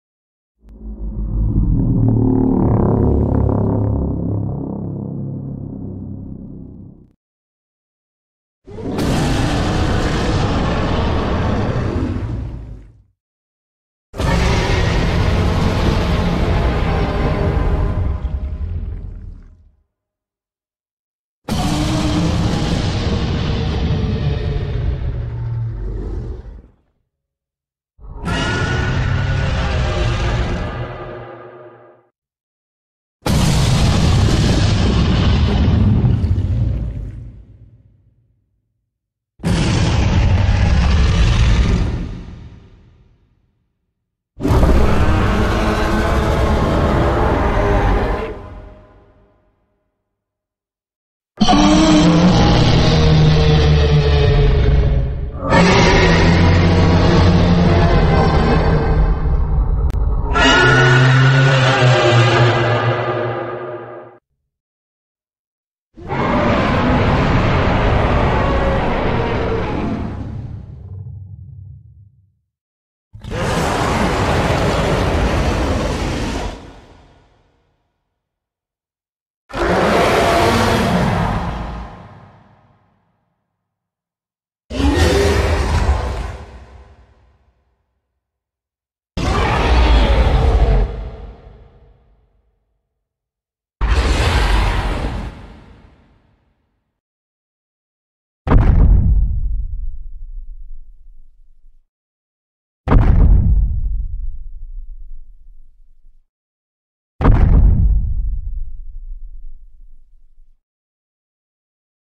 Звуковые эффекты Ктулху для монтажа